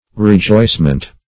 Rejoicement \Re*joice"ment\ (-ment), n.